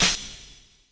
Closed Hats
DSFD_HAT (2).wav